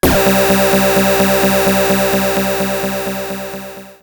RetroGamesSoundFX / Hum / Hum27.wav
Hum27.wav